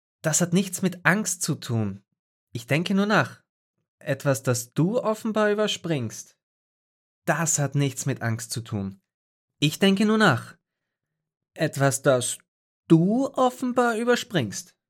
Obs für nen 16jährigen reicht ... nun ich werf mal eine Probe in den Ring für Tarin your_browser_is_not_able_to_play_this_audio Schönen Sonntag *wink*